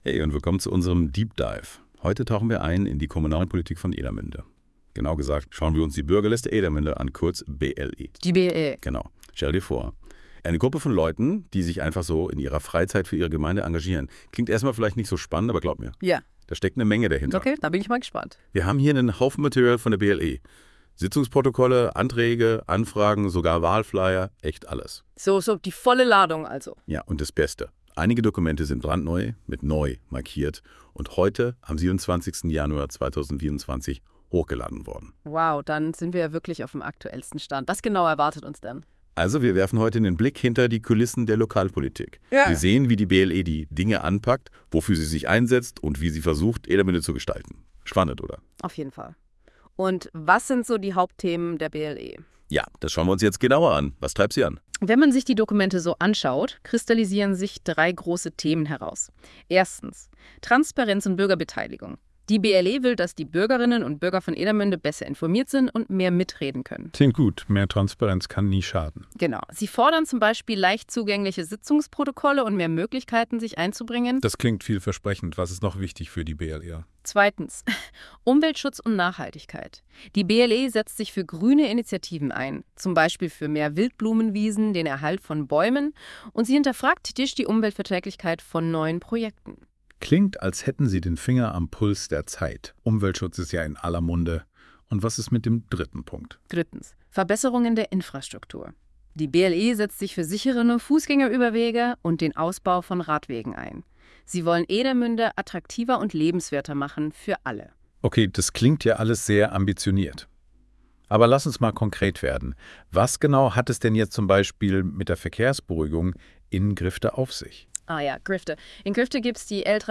Details Kategorie: Podcast Podcast über die Aktivitäten der Bürgerliste Edermünde Hinweis: Dieser Podcast wurde mit Hilfe einer künstlichen Intelligenz erzeugt.